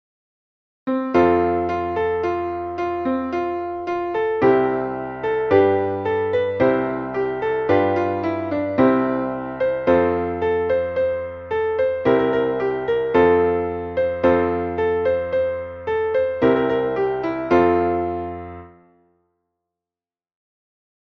Traditionelles Kinderlied